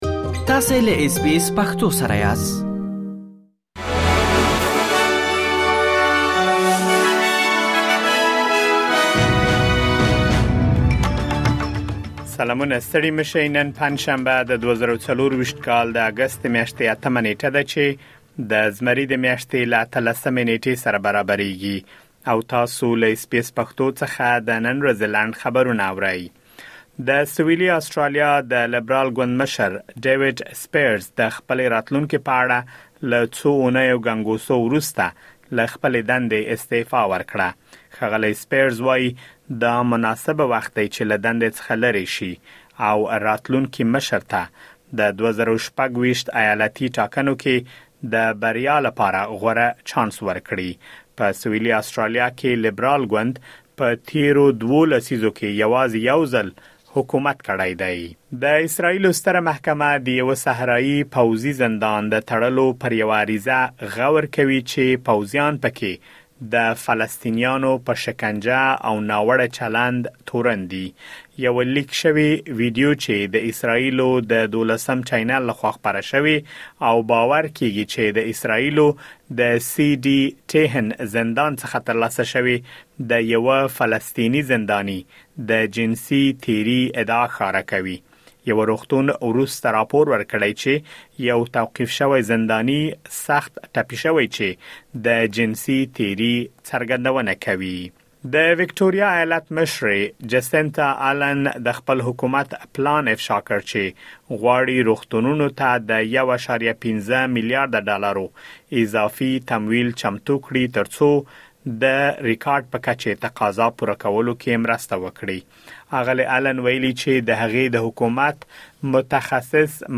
د اس بي اس پښتو د نن ورځې لنډ خبرونه|۸ اګسټ ۲۰۲۴